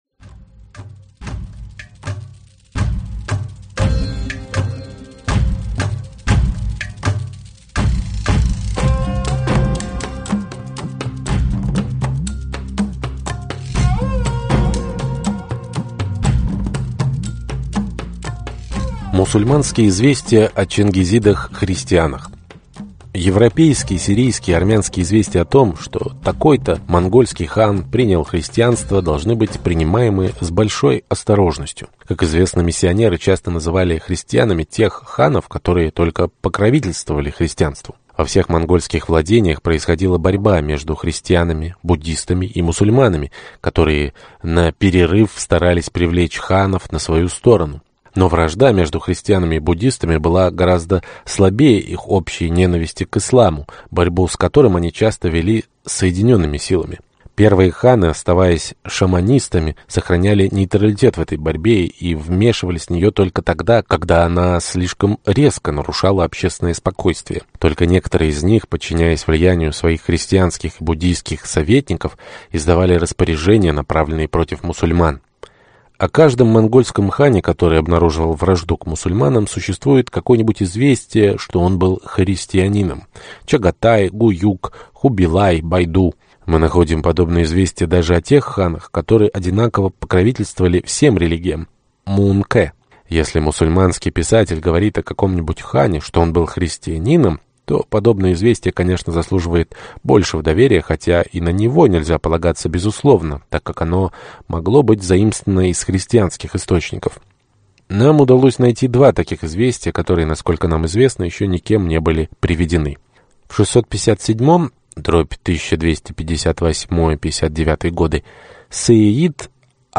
Аудиокнига Культура мусульманства и Тюрки | Библиотека аудиокниг